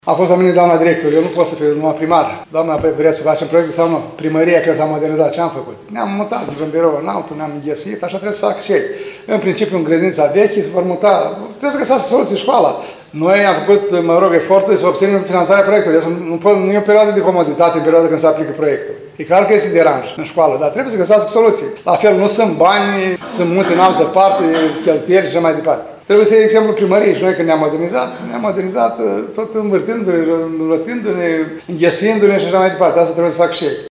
Primarul ION LUNGU a declarat că – pe parcursul a 18 luni, cât vor dura lucrările – elevii se vor muta în spațiile fostei grădinițe a colegiului.